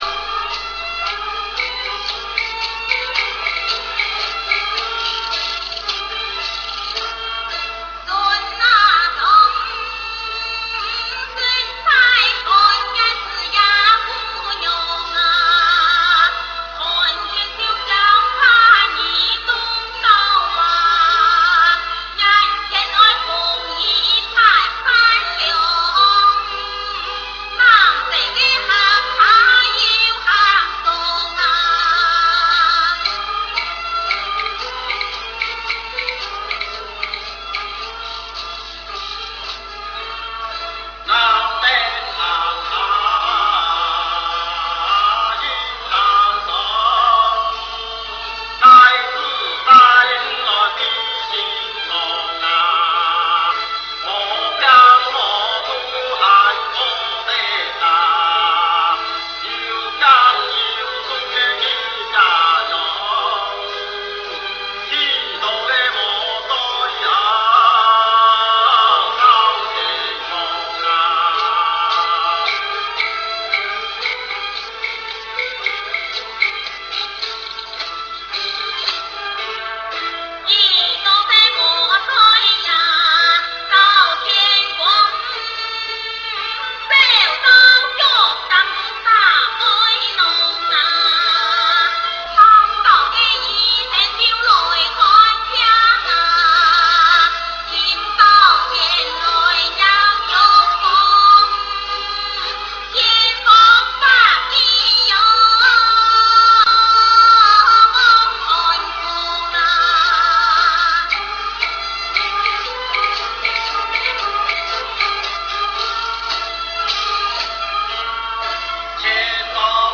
Hakka Hill Songs
For completeness, the song is sung in Chiung Hiu (lit. Pine Mouth) Hakka.
The song is sung by a male and female duo. They alternate between stanzas. In this example, the singer alludes to bamboo sticks, which is part of the accompanying music.
At the end, both singers do alternate lines of the same end stanza, finishing with both singing the same line.